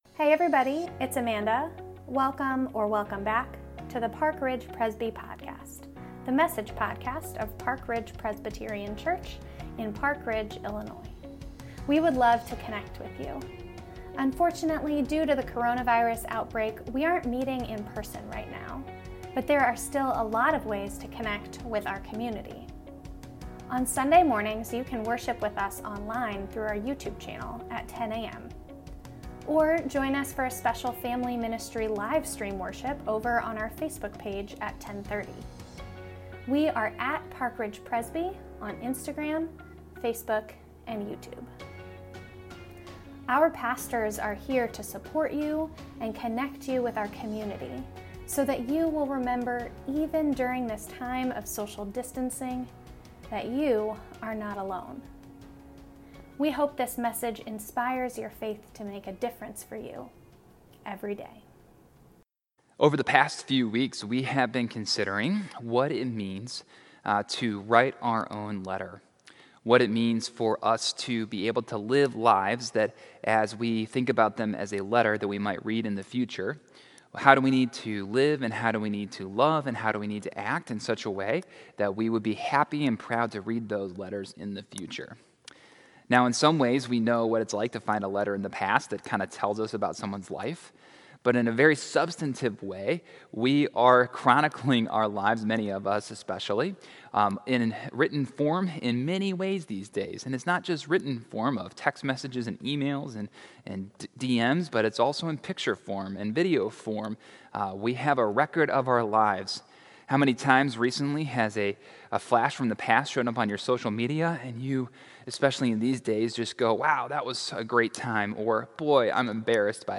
a message